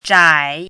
chinese-voice - 汉字语音库
zhai3.mp3